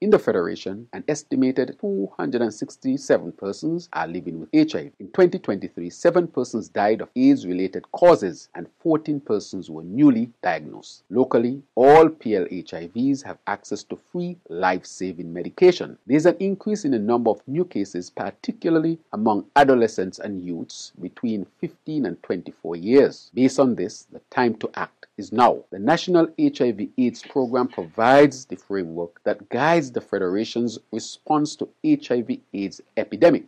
Prime Minister and Minister with responsibilities for Health, the Hon. Dr. Terrance Drew said in an address: